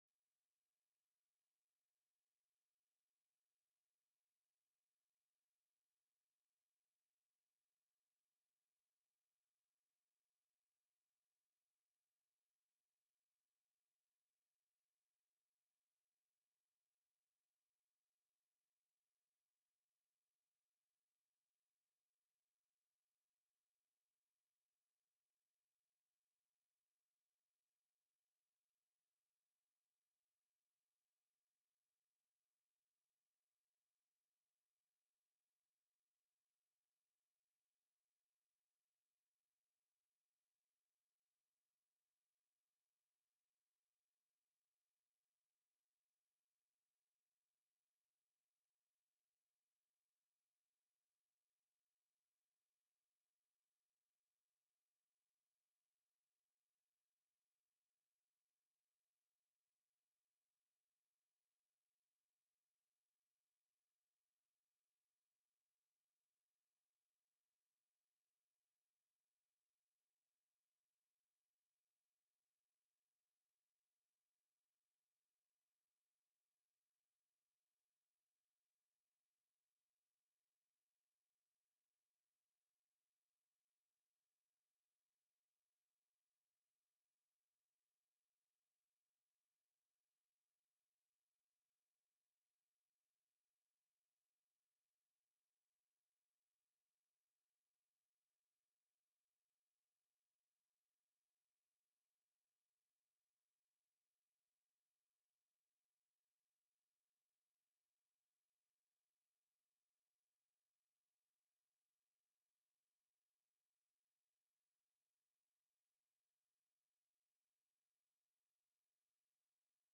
05/07/2025 05:15 PM Senate LEGISLATIVE COUNCIL
The audio recordings are captured by our records offices as the official record of the meeting and will have more accurate timestamps.